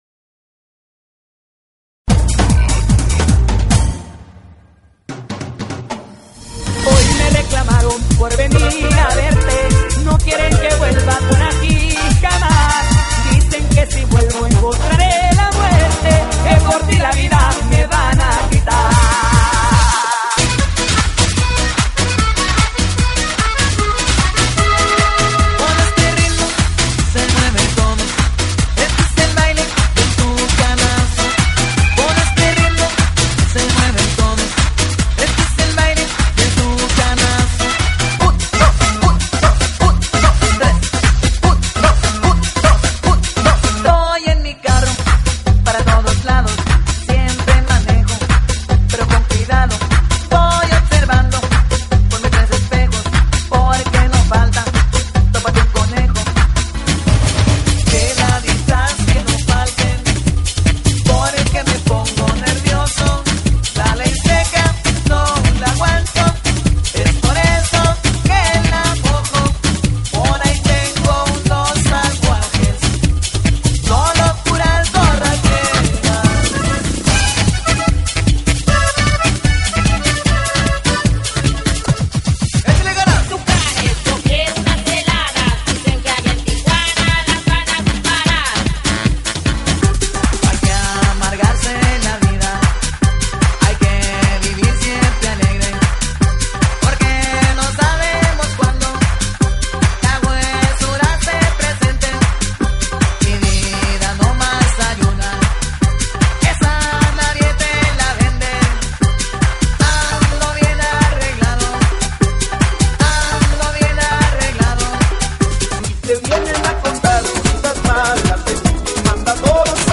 GENERO: LATINO
BANDA REMIX